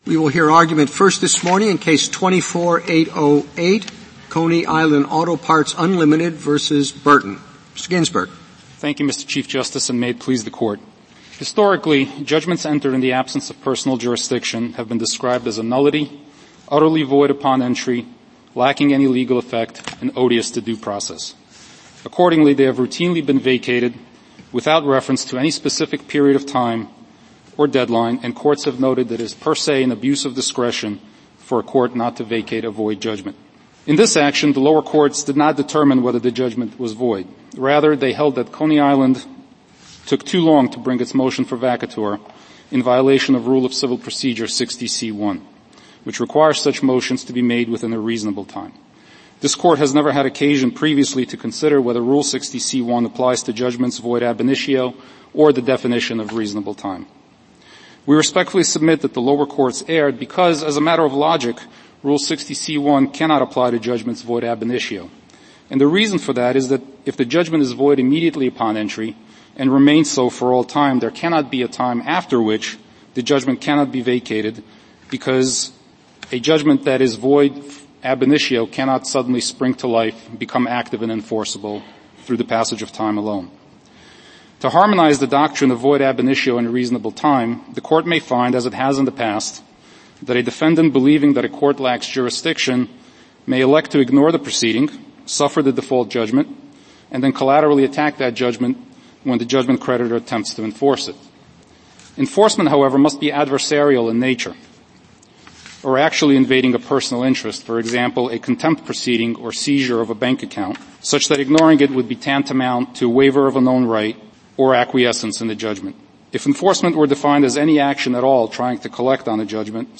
Supreme Court Oral Arguments